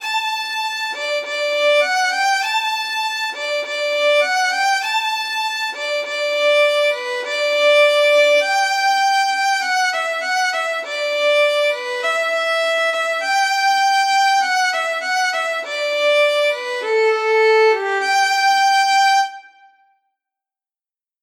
17_countrylass_20124_refrain_motherbeguiles_fiddle.mp3 (831.56 KB)
Audio fiddle of transcribed recording of refrain of stanza 1 of “The Countrey Lasse”